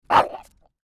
Звуки мопса
Звук лая мопса мопс лает